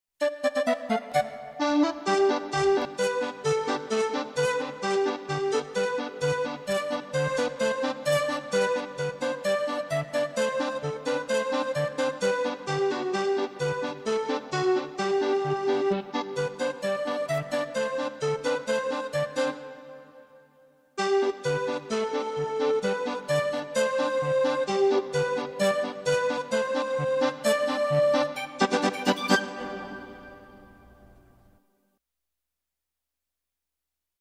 Welcome_to_Shido_(Instrumental).mp3